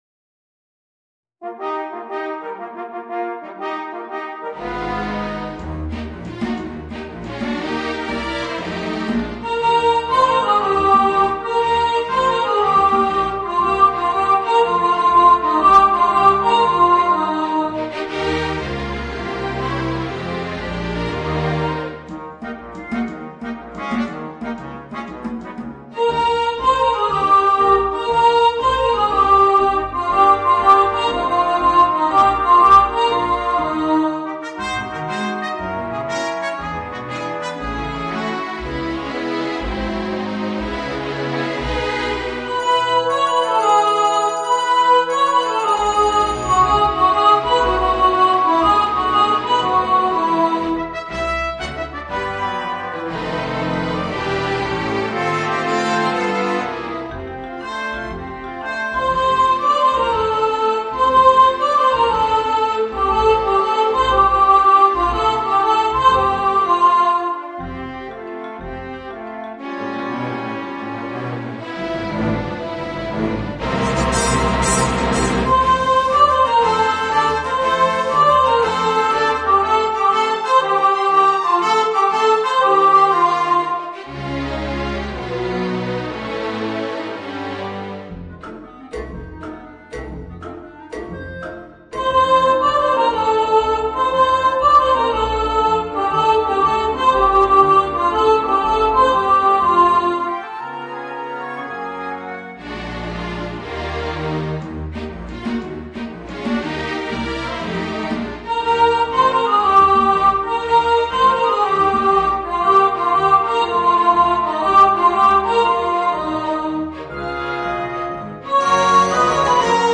Voicing: Children's Choir and Orchestra